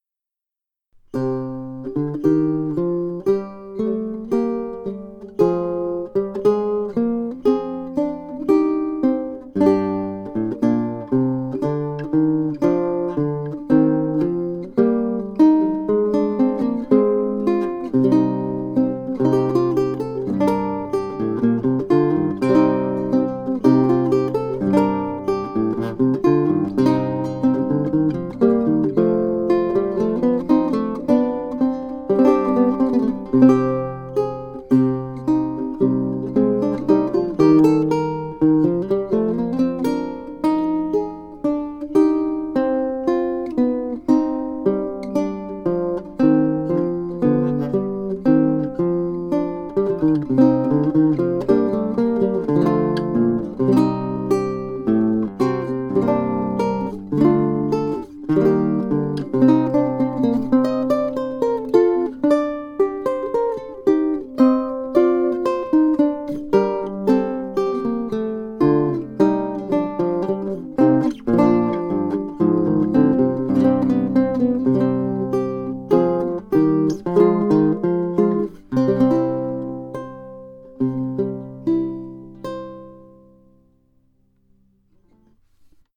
ビウエラ
表も裏もサイドも全部屋久杉で、ネックはマホガニー、指板はパープルハート。
da_crema_recercar_duodecimo_07_vihuela.mp3